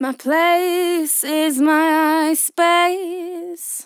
Vocal Sample DISCO VIBES
Categories: Vocals
dry
female